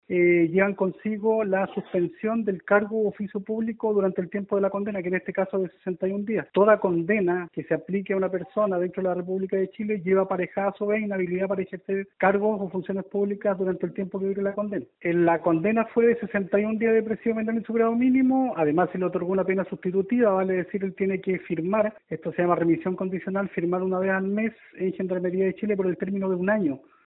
En el desarrollo de la audiencia el imputado asumió su responsabilidad, por lo tanto, la ley otorga una circunstancia atenuante, explicó el fiscal y aclaró que al ser condenado quedó inhabilitado para ejercer cargos públicos mientras dure la condena.